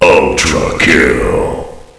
counter-strike-ultra-kill_25771.mp3